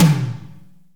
TOM ROOMY 0G.wav